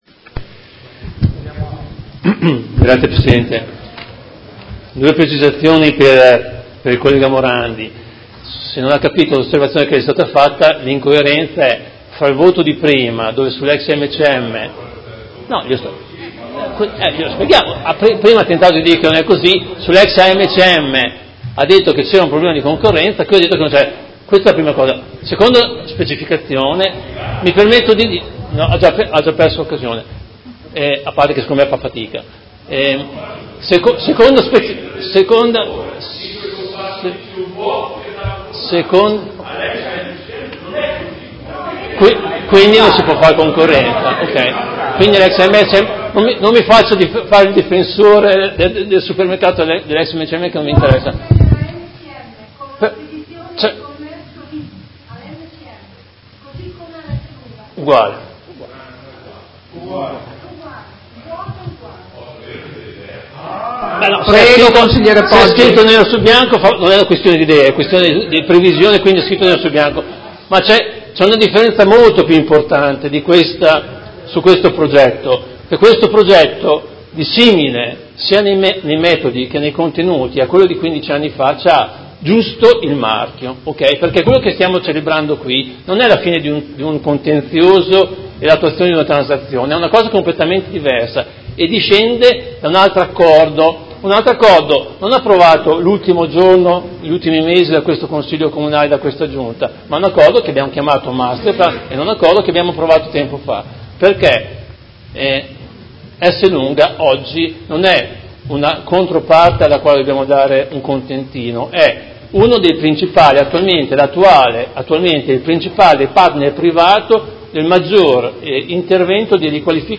Fabio Poggi — Sito Audio Consiglio Comunale
Seduta del 17/12/2018 Dichiarazione di voto.